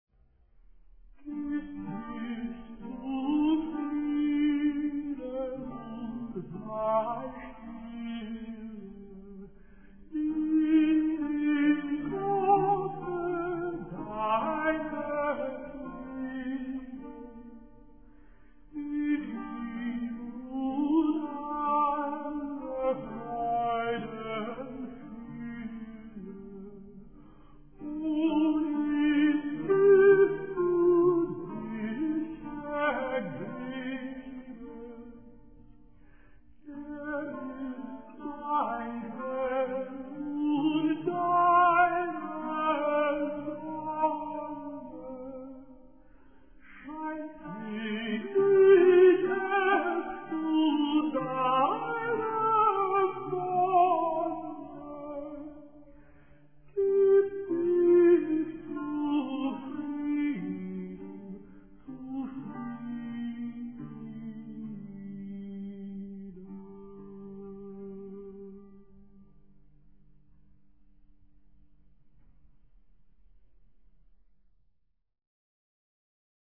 mp3 mono 8kbps